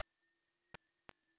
Abstract Rhythm 22.wav